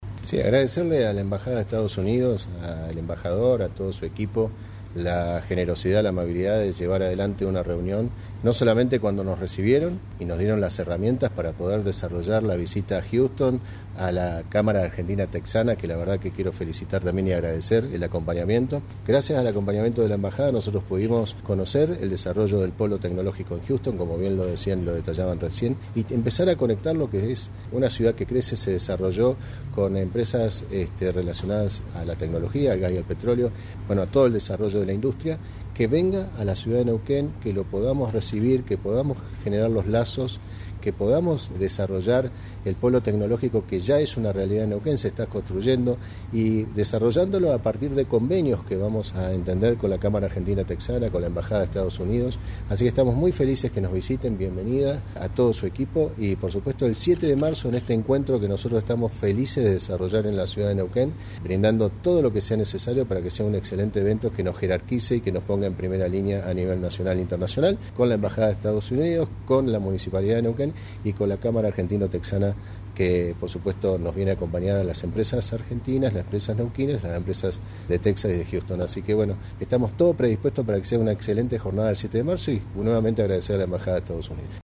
Mariano Gaido, Intendente.
Mariano-Gaido-EDITADO-Visita.mp3